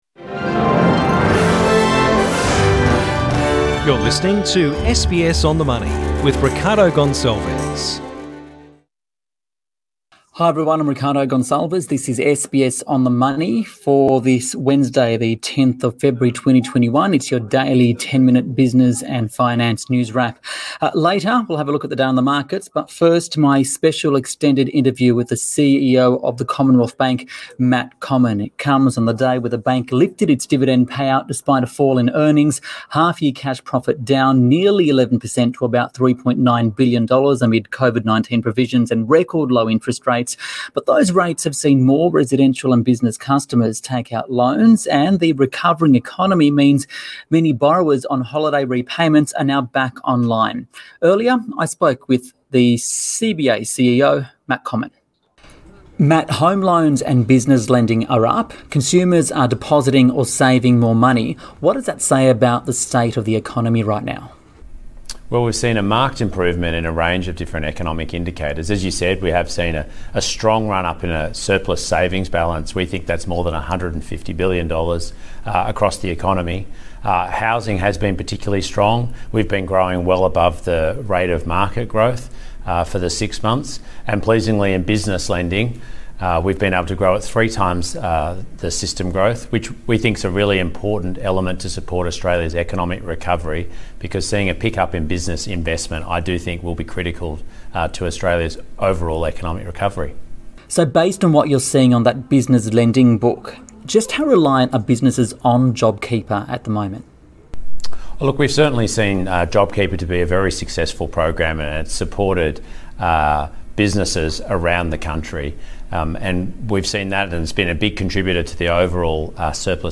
SBS On the Money special interview: Matt Comyn, Commonwewalth Bank CEO